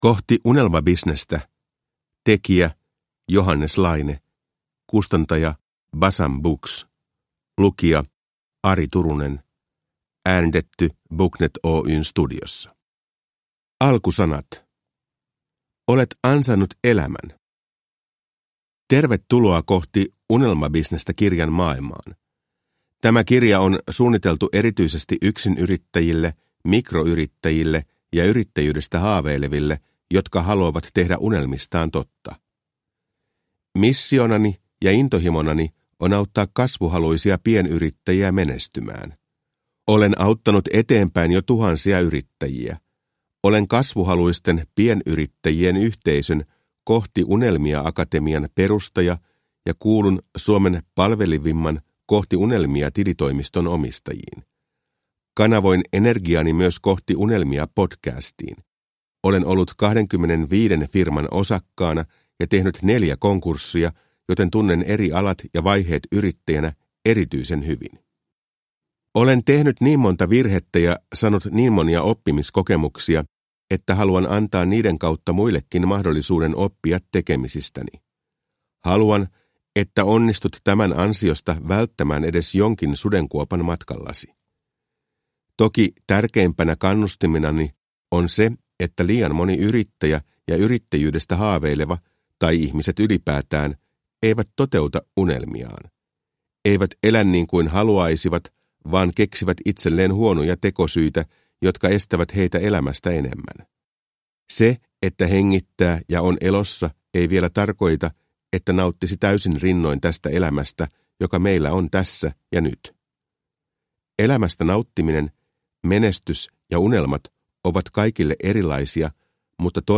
Kohti unelmabisnestä – Ljudbok